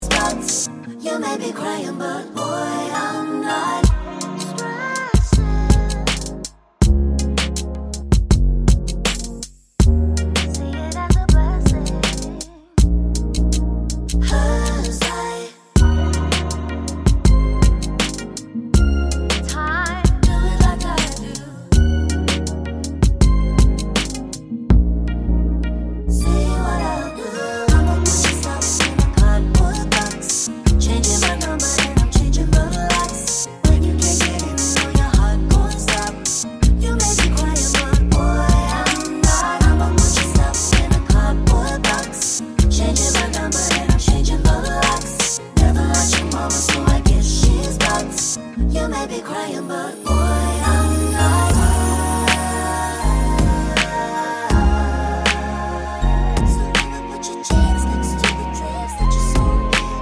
With Clean Backing Vocals Pop (2020s) 2:46 Buy £1.50